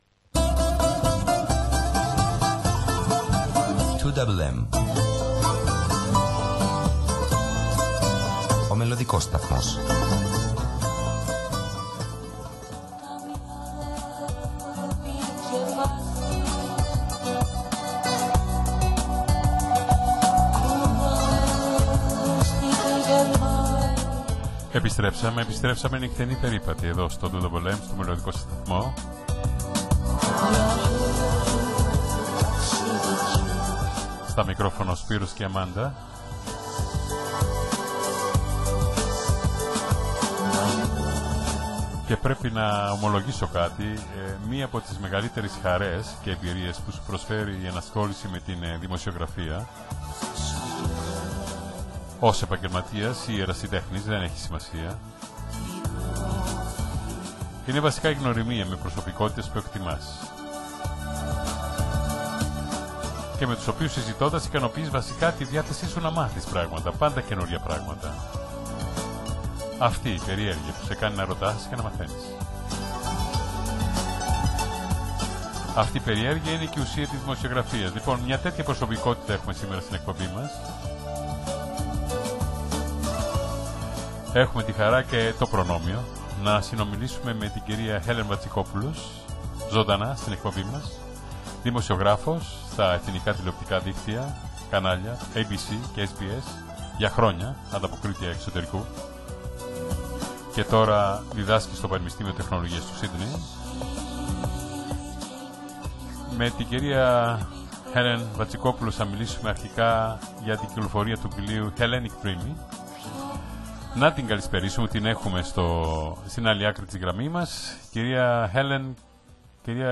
του ελληνόφωνου ραδιοφωνικού σταθμού του Σίδνει